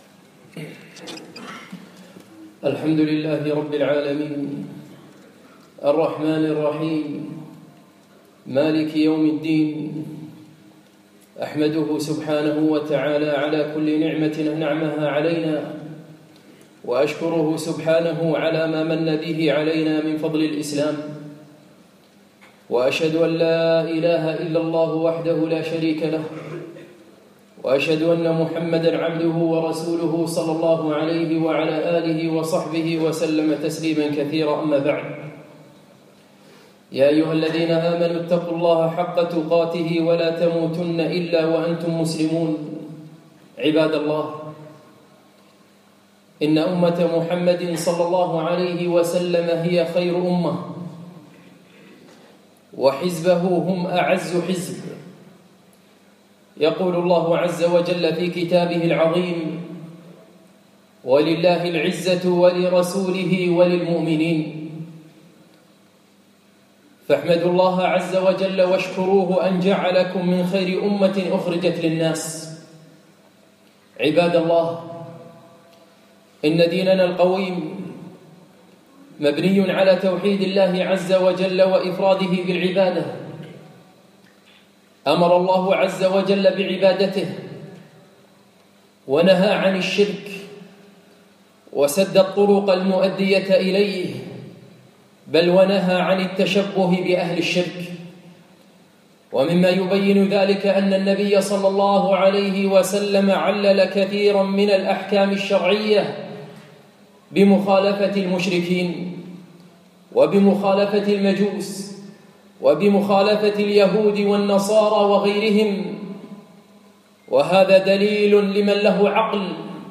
الجمعة 24 ربيع الأول 1438 مسجد ضاحية الفردوس الكويت